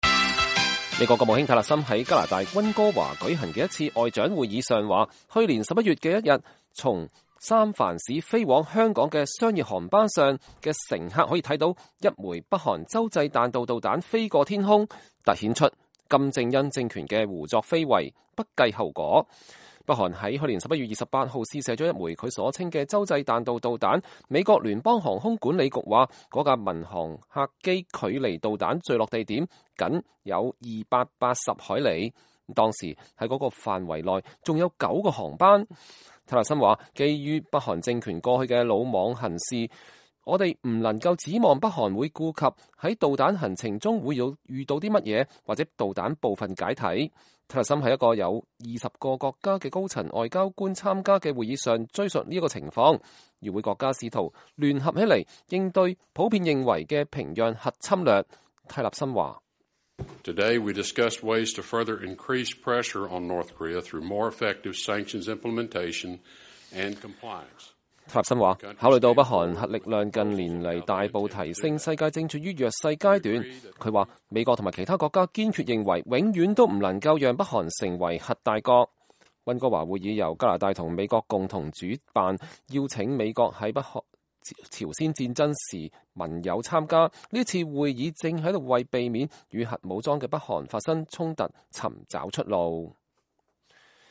美國國務卿蒂勒森(右)在溫哥華會議上講話 (2018年1月16日)